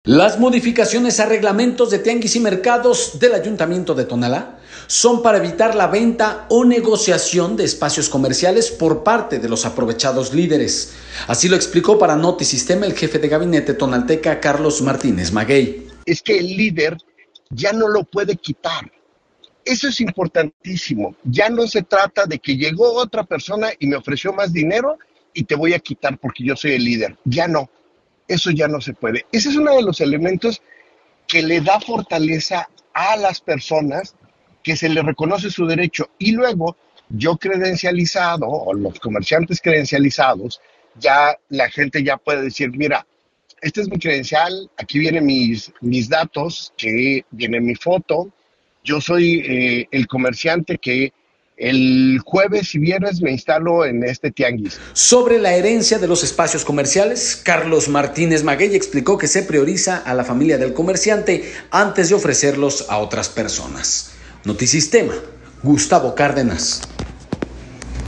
Las modificaciones a reglamentos de tianguis y mercados del Ayuntamiento de Tonalá son para evitar la venta o negociación de espacios comerciales por parte de los aprovechados líderes, así lo explicó para Notisistema el jefe de Gabinete tonalteca, Carlos Martínez Maguey.